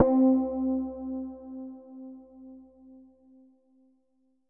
SPOOKY C3.wav